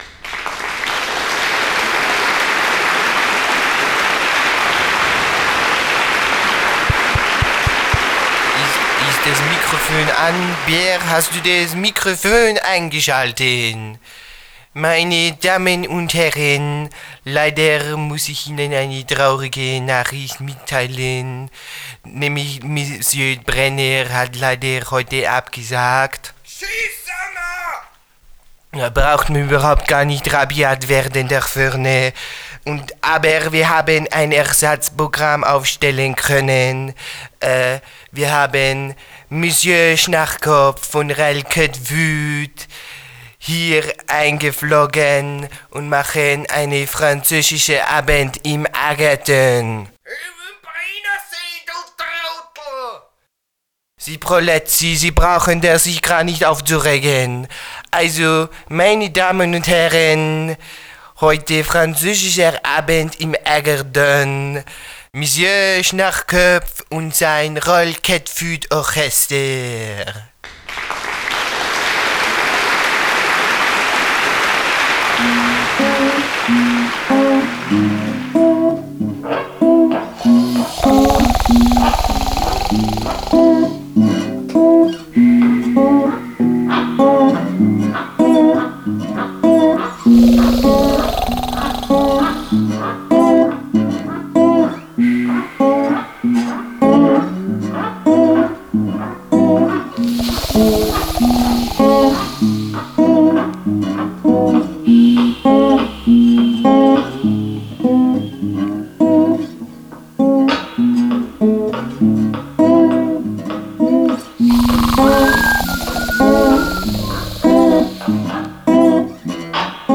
Genre: Freie Musik - Französische Avantgarde